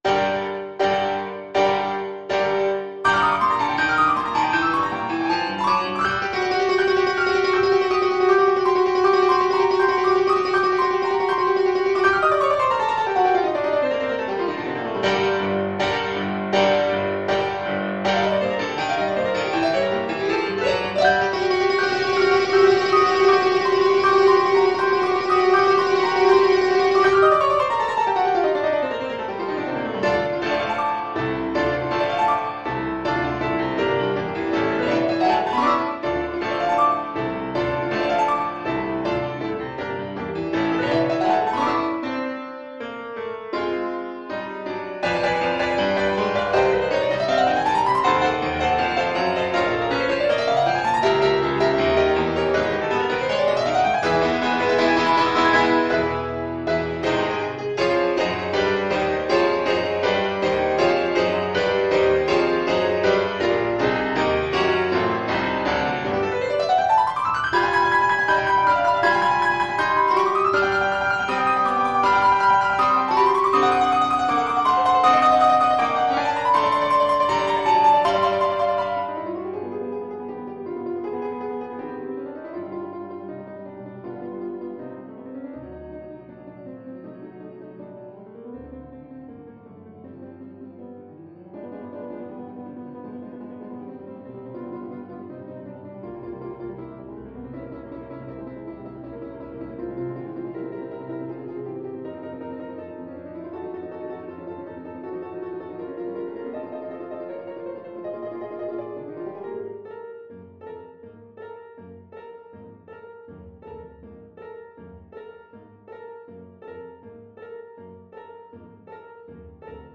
Play (or use space bar on your keyboard) Pause Music Playalong - Piano Accompaniment Playalong Band Accompaniment not yet available reset tempo print settings full screen
Allegro agitato (=80) (View more music marked Allegro)
G minor (Sounding Pitch) D minor (French Horn in F) (View more G minor Music for French Horn )
Classical (View more Classical French Horn Music)